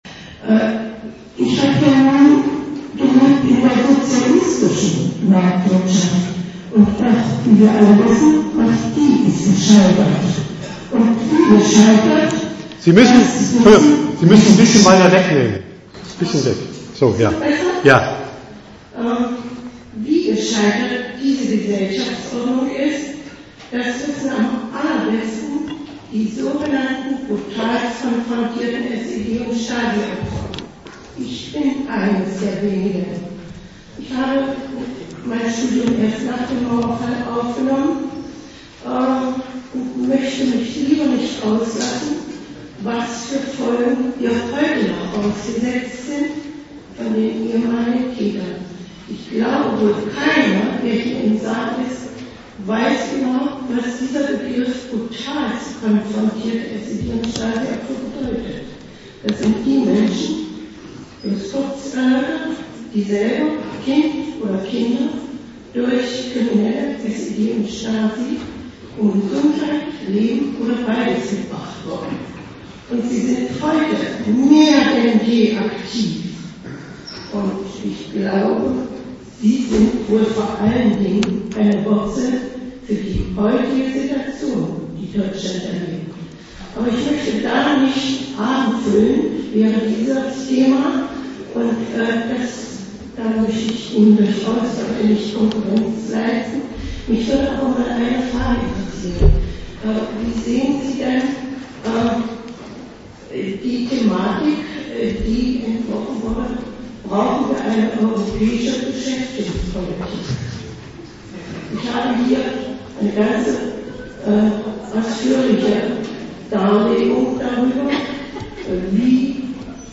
Dozent Gastreferenten der Zeitschrift GegenStandpunkt Aus gegebenem Anlass eine etwas andere - Einführung in die Kritik der politischen Ökonomie des Kapitalismus